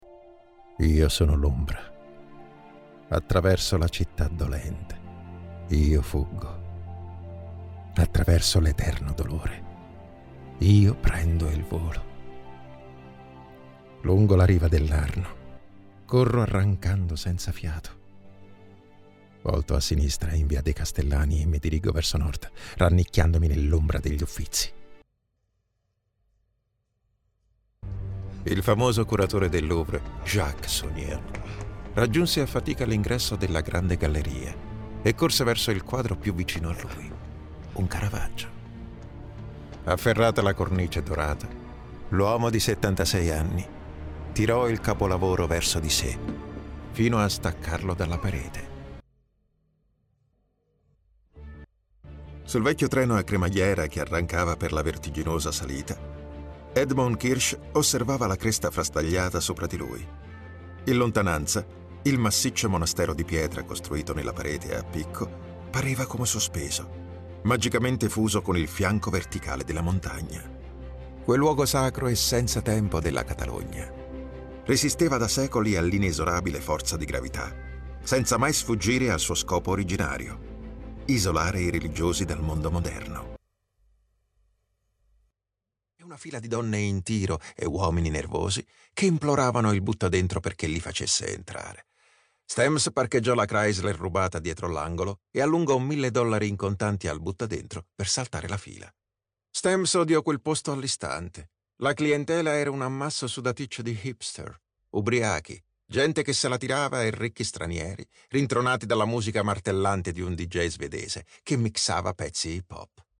Male
Versatile, Corporate, Deep, Warm
Audio equipment: The recordings are made in my home studio equipped with soundproof booth, Neumann tlm 103 microphone, Apollo MKII SOUND CARD which guarantees white quality